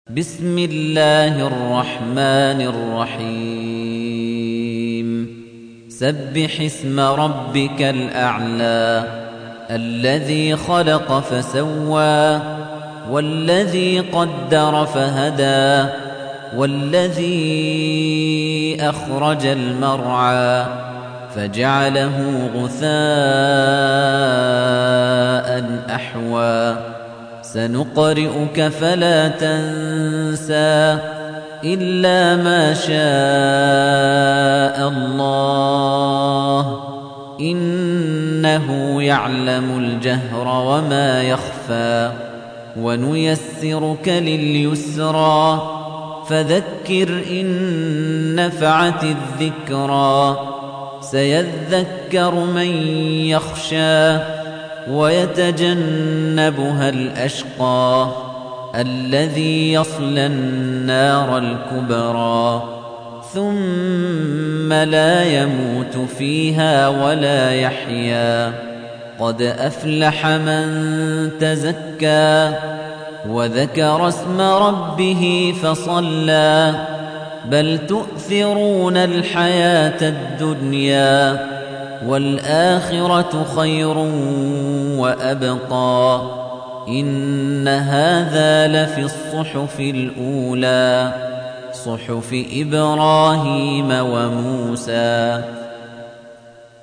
تحميل : 87. سورة الأعلى / القارئ خليفة الطنيجي / القرآن الكريم / موقع يا حسين